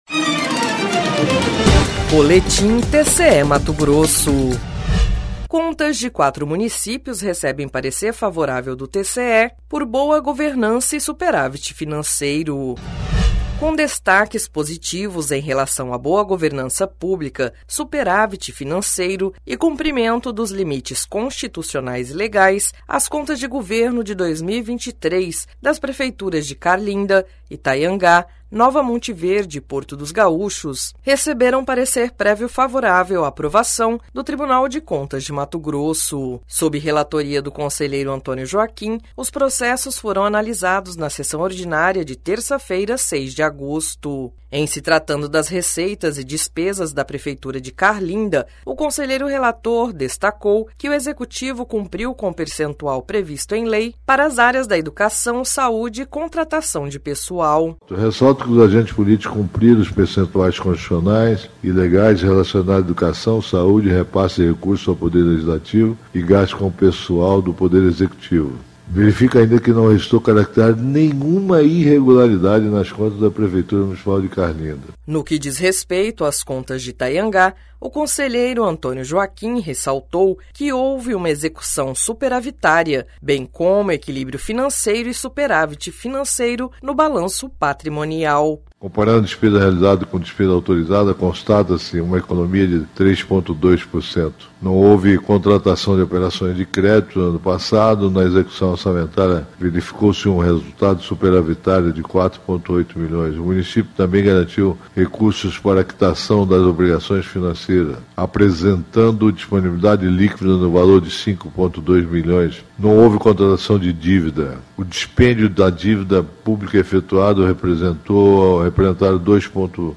Sonora: Antonio Joaquim – conselheiro do TCE-MT